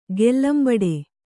♪ gellambaḍe